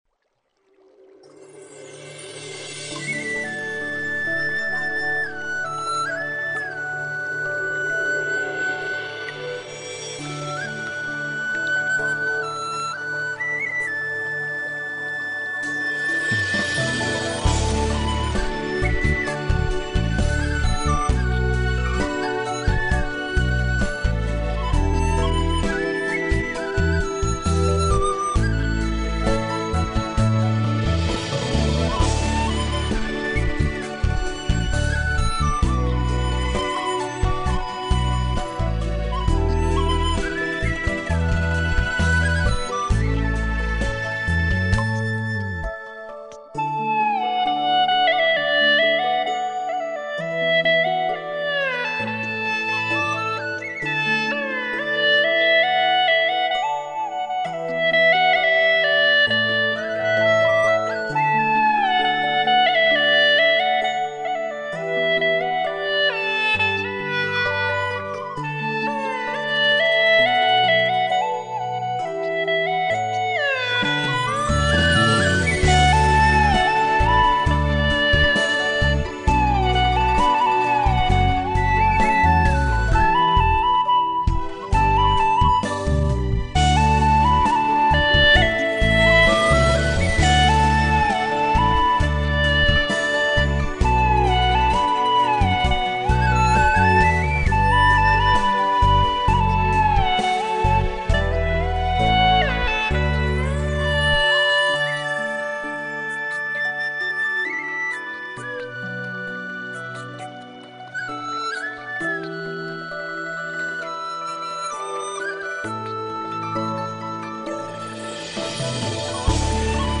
调式 : D